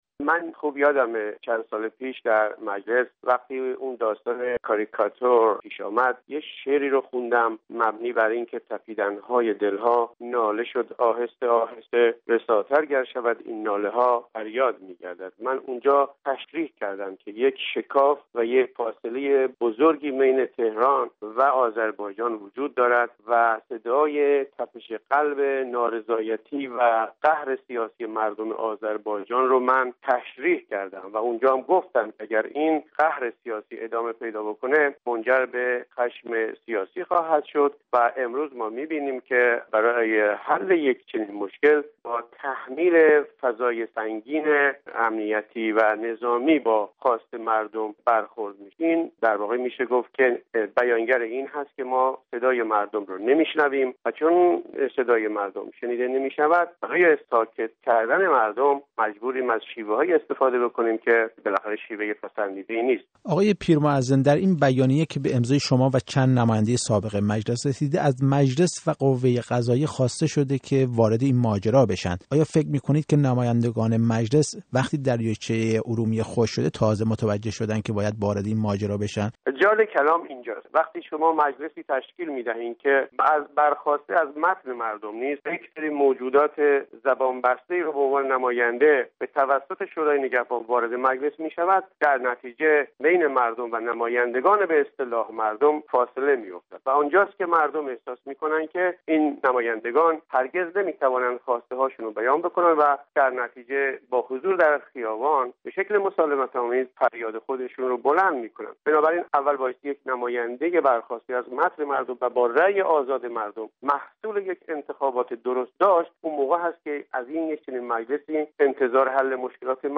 گفت‌گوی